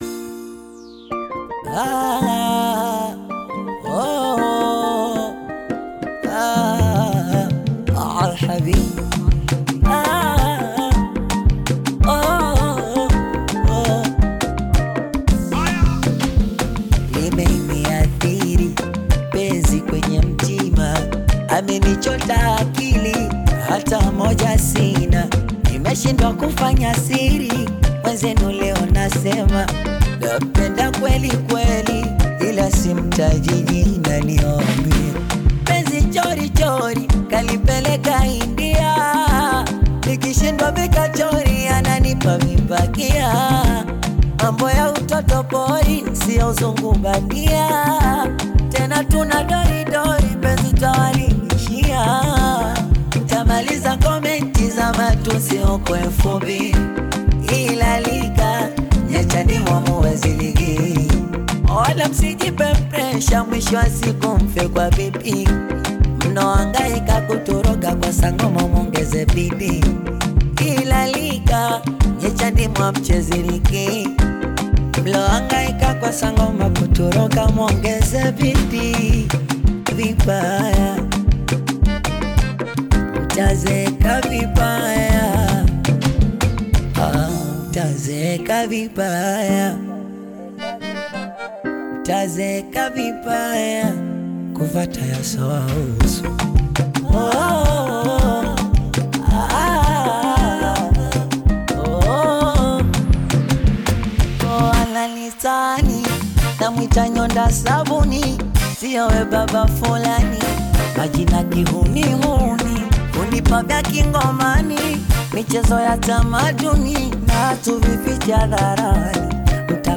soulful vocals and catchy beats.